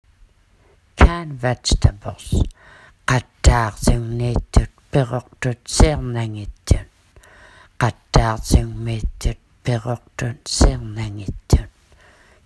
(Scan the QR code or click the image above to hear "canned vegetables" pronounced in the Inuktitut South Baffin dialect)